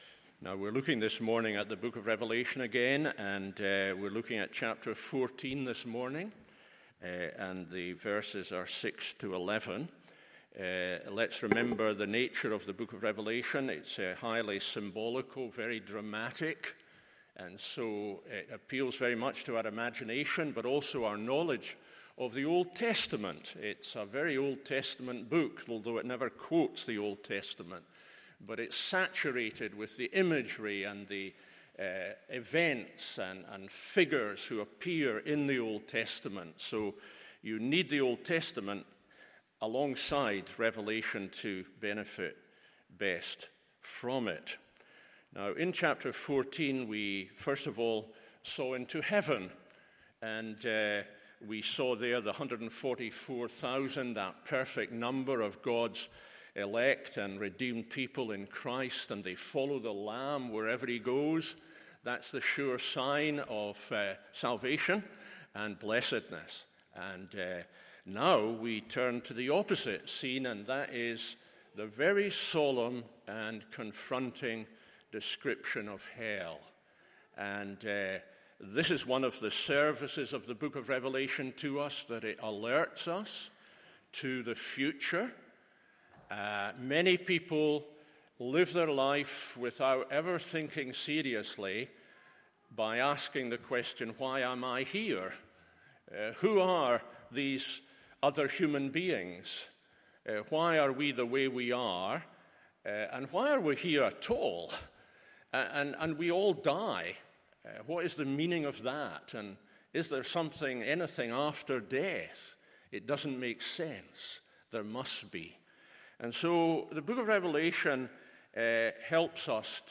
MORNING SERVICE Revelation 14:6-11 Supplementary readings Genesis 19:12-26 Luke 16:19-31…